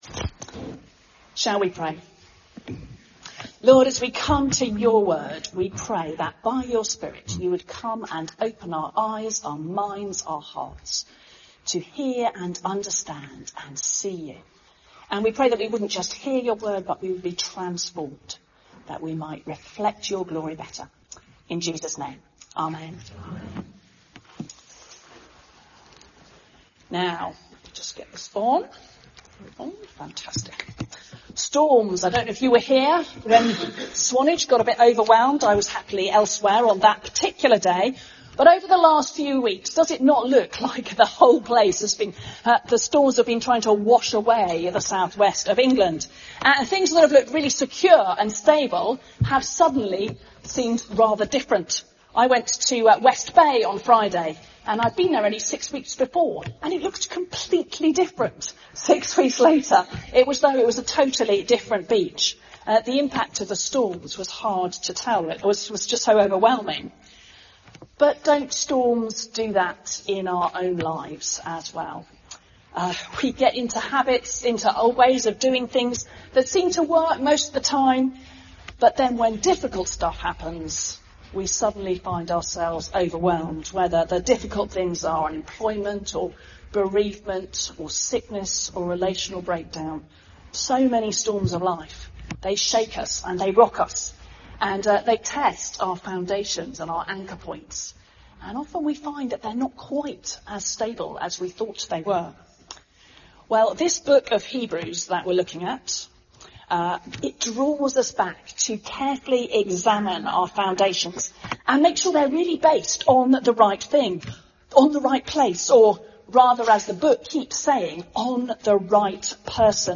Sunday Sermon 22nd February 2026